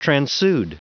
Prononciation du mot transude en anglais (fichier audio)
Prononciation du mot : transude